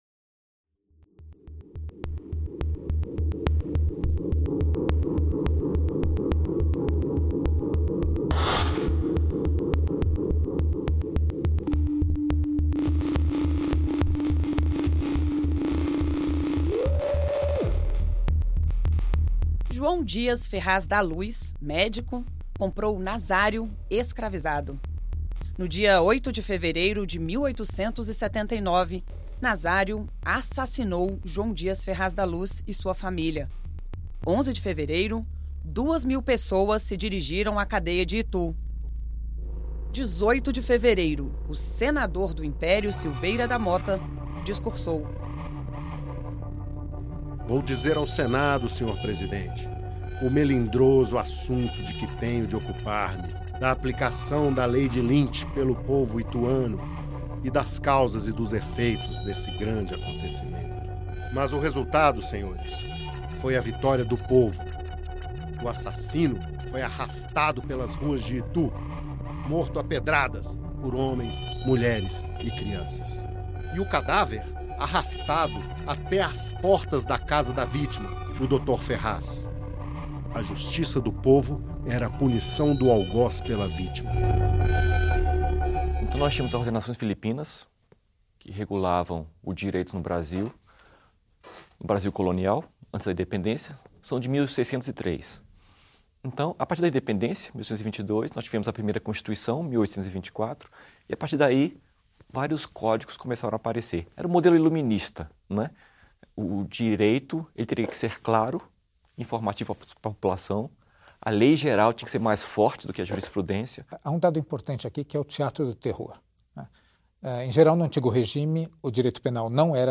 TV Senado | Pena de morte no Império - Documentário Completo
Pena-de-morte-no-Imperio-Documentario-Completo-szh24bKJnVA.mp3